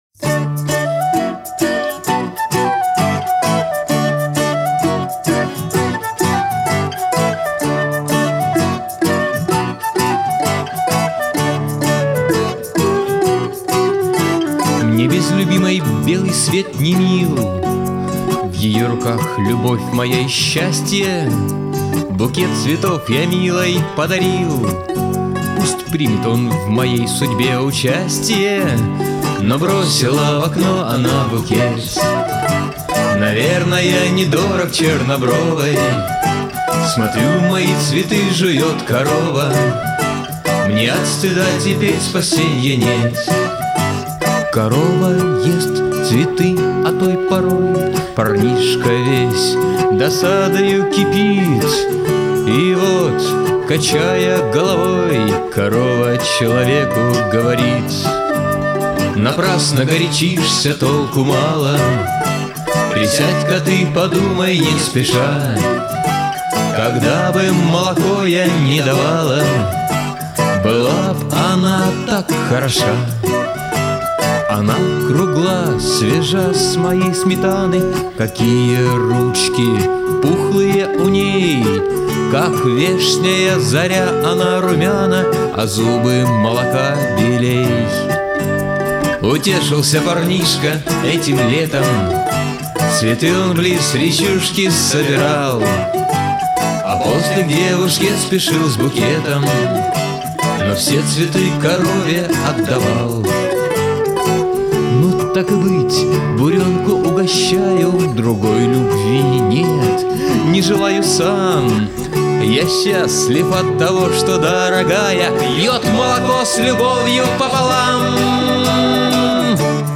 А вот такую жизнерадостную песню на его слова я до сегодняшнего дня не слышала...